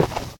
step_stone.1.ogg